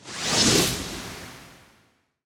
UI_BronzeVanish.ogg